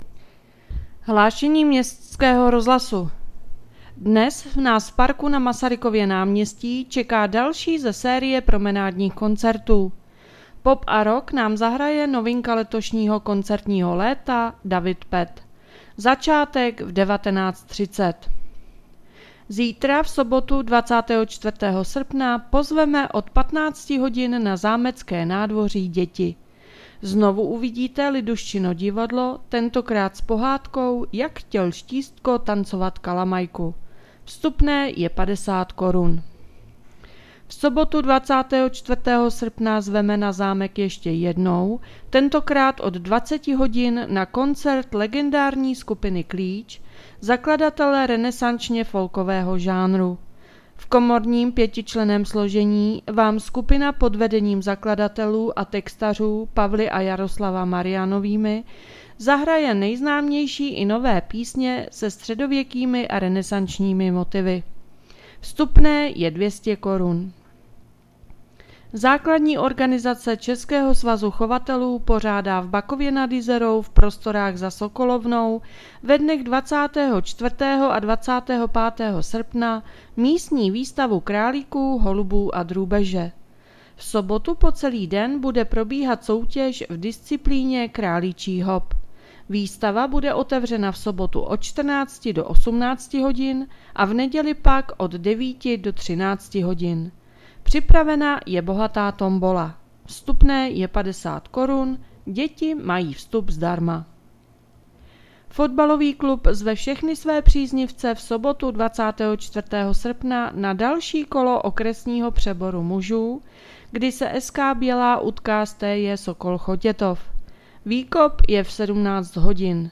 Hlášení městského rozhlasu 23.8.2024